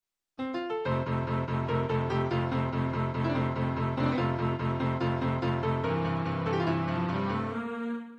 アレグロの主題は下のように書き換えてみると、いったん下降して上行する分散和音といえる。